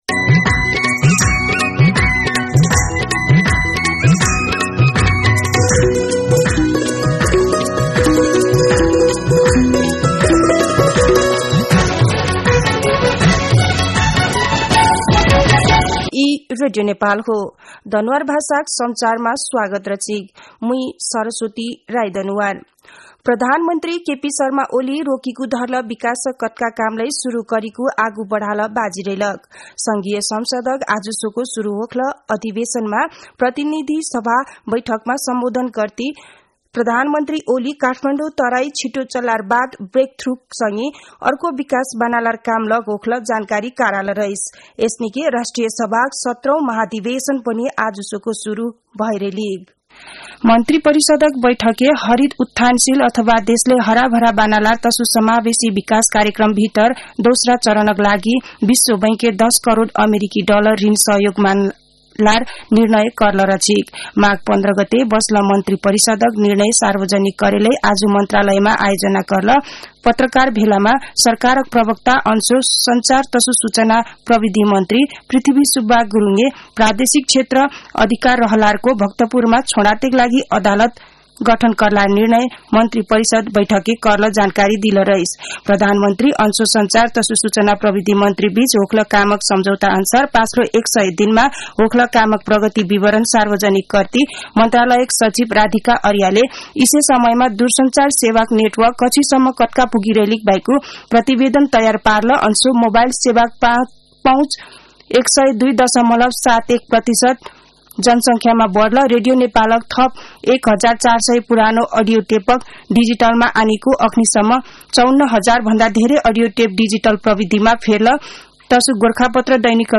दनुवार भाषामा समाचार : १९ माघ , २०८१
Danuwar-News-3.mp3